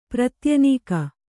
♪ pratyanīka